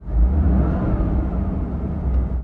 CosmicRageSounds / ogg / general / cars / rev2.ogg